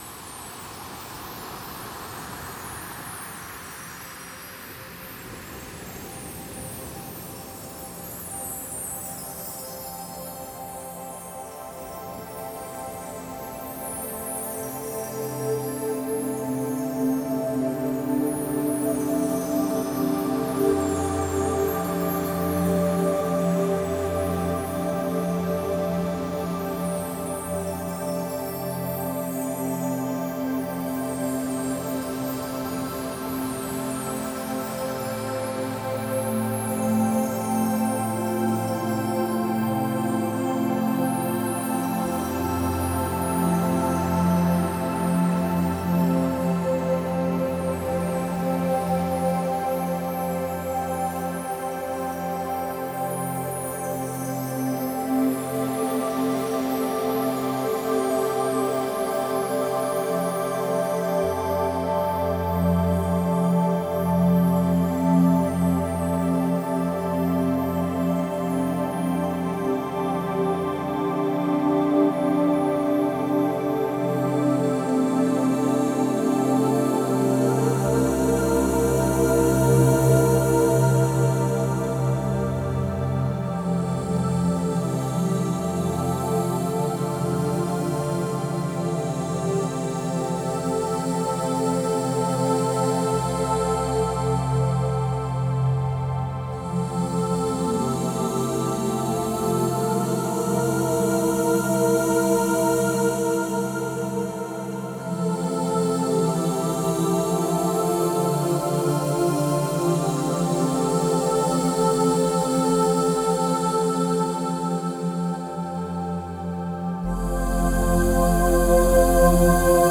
New age Нью эйдж Медитативная музыка